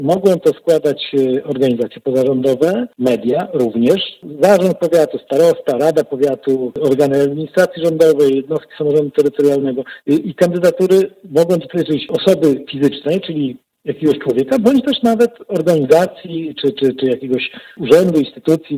Marek Chojnowski – starosta powiatu ełckiego.